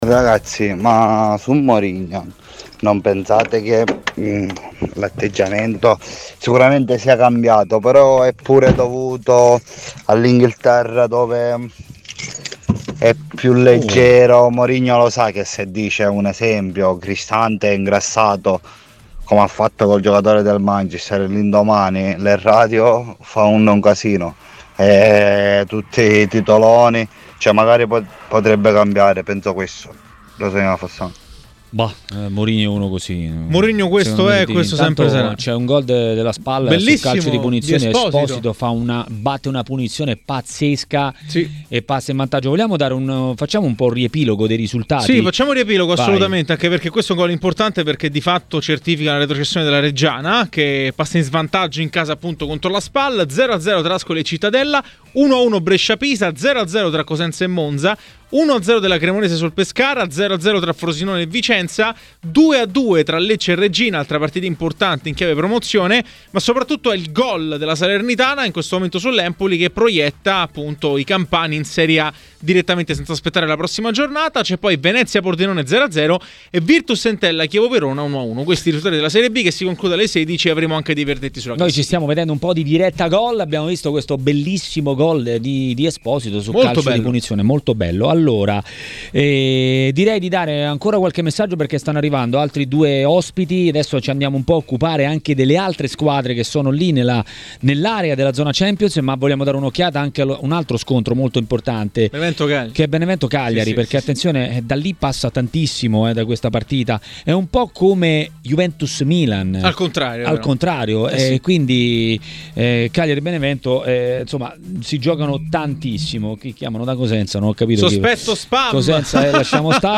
A commentare la sfida tra Benevento e Cagliari (ma non solo) a Maracanà, nel pomeriggio di TMW Radio, è stato l'ex calciatore Jeda.